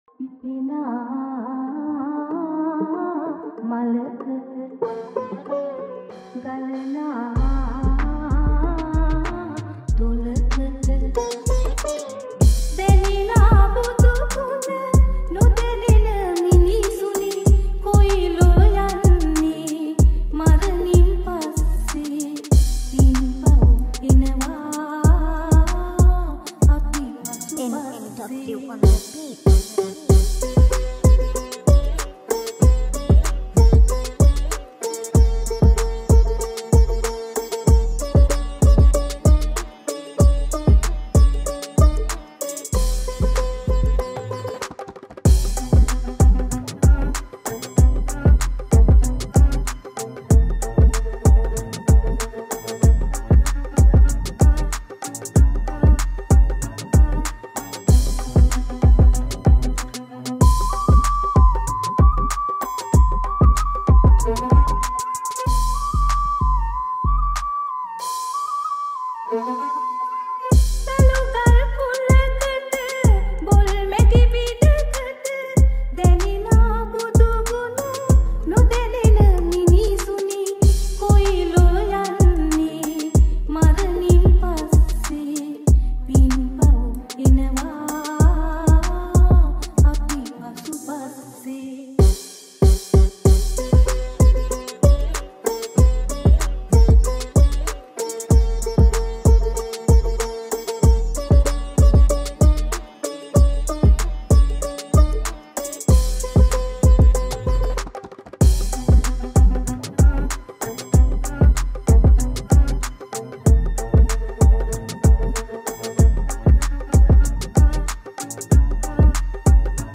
Releted Files Of Sinhala Old Dj Remix Mp3 Songs